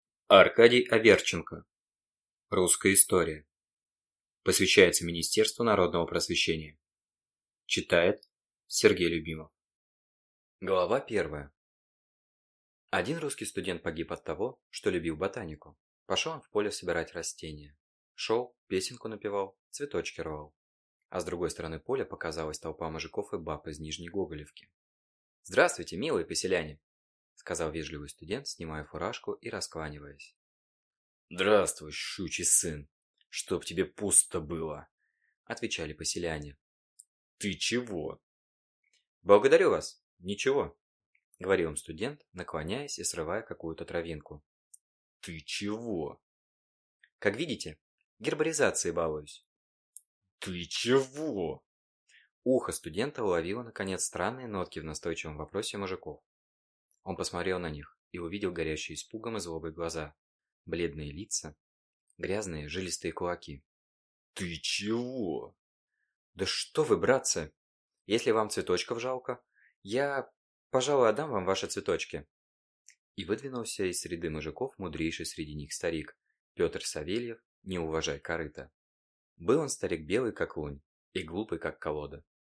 Аудиокнига Русская история | Библиотека аудиокниг